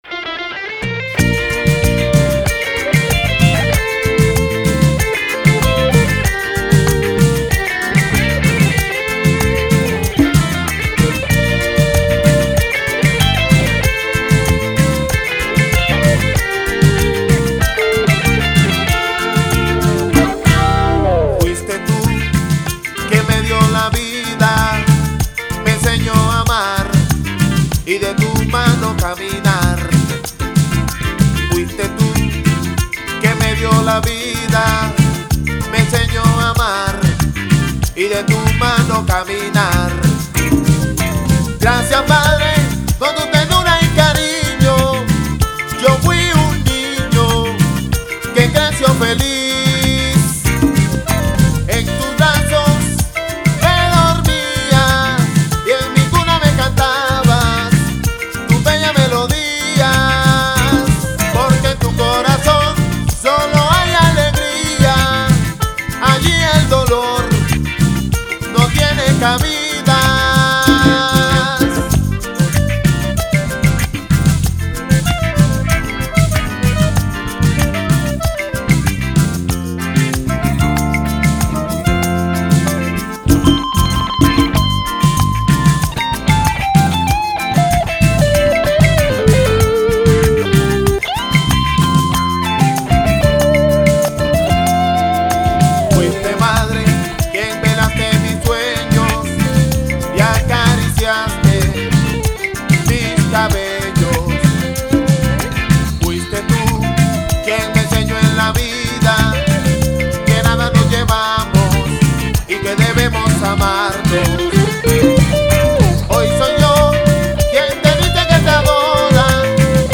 Tropical